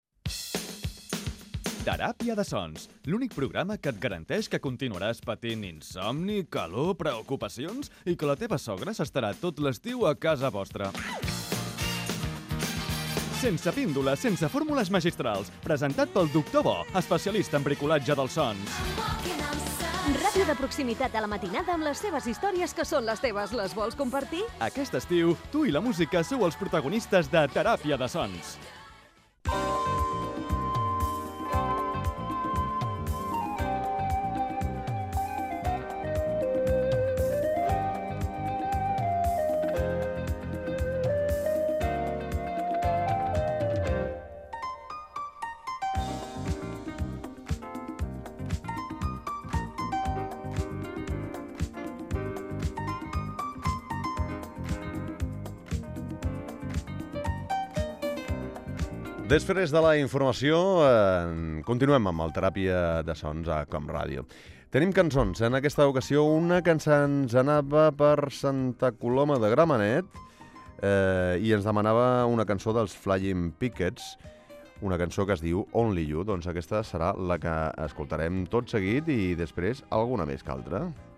Indicatiu i inici de la segona hora del programa i atenció a les peticions dels oients
Entreteniment